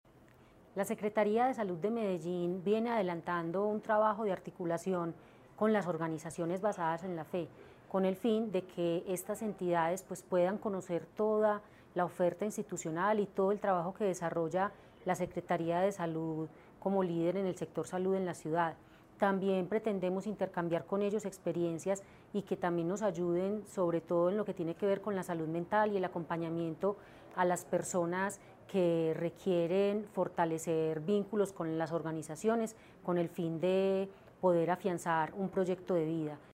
Palabras de Natalia López Delgado, secretaria de Salud